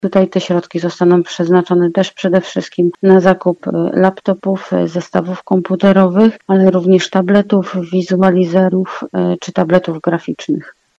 Mówi wiceprezydent miasta Małgorzata Domagała: